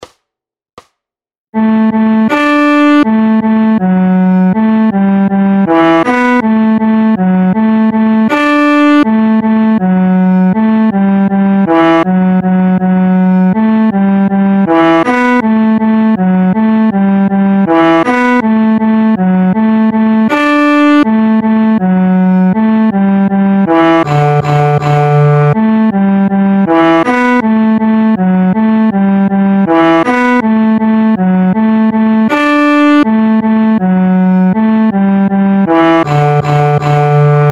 Aranžmá Noty na violoncello
Hudební žánr Lidovky